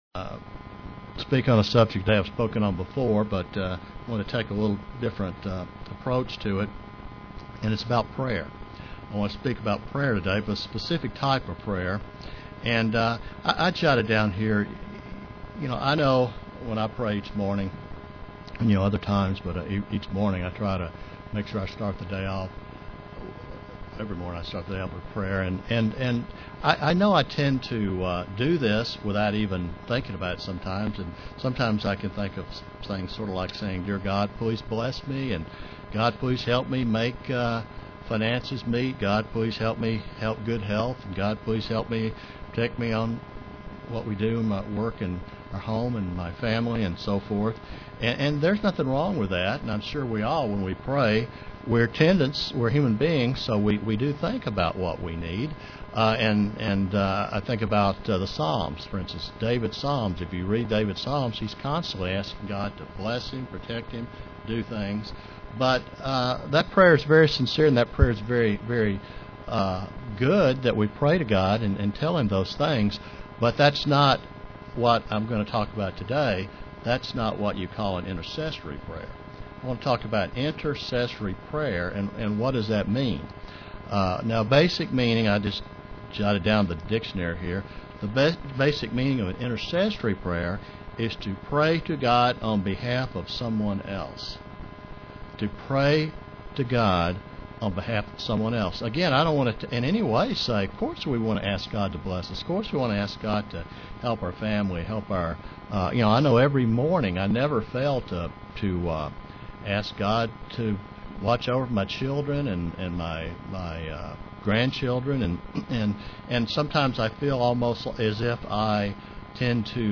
Given in Knoxville, TN
Print We have to trust God and not let other people influence us UCG Sermon Studying the bible?